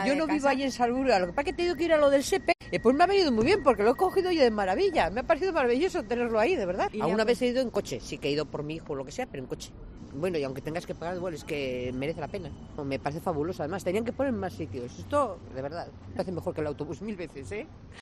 usuaria del tranvía a Salburua